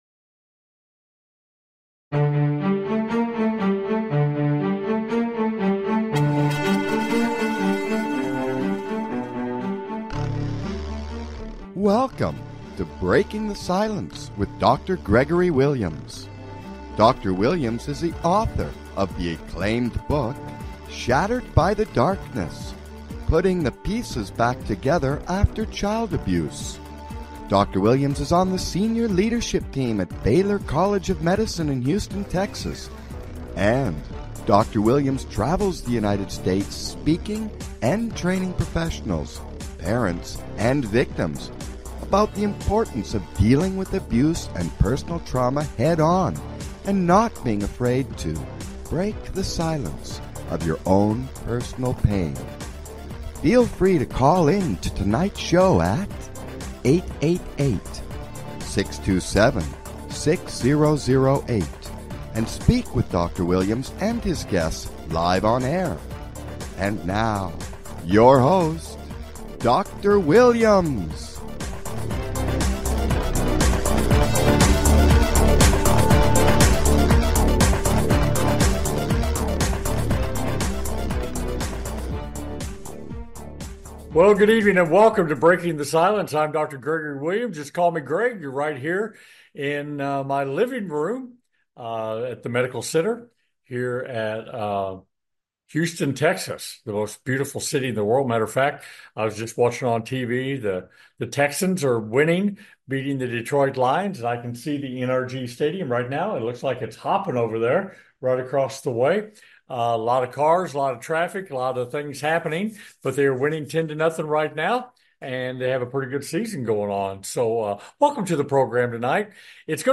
Breaking the Silence Talk Show